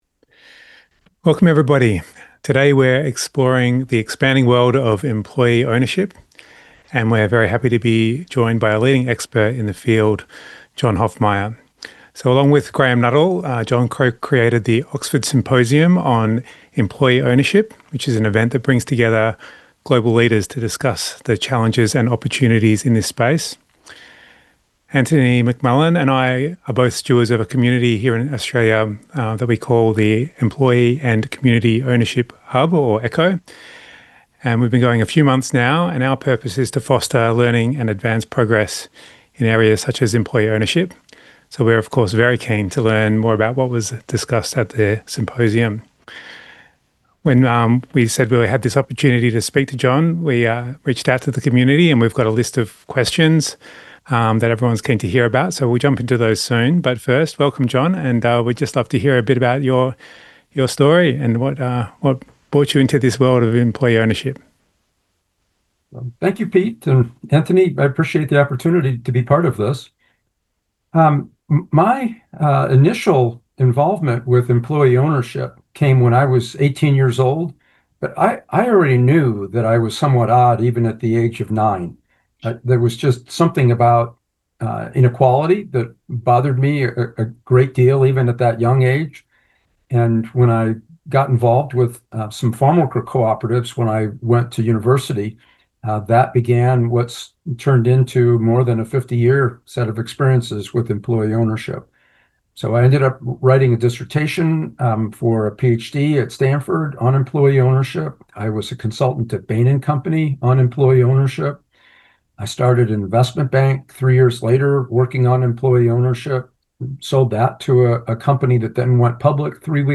We are pleased to share a thought-provoking conversation